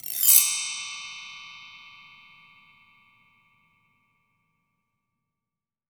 BELL TREE S1.WAV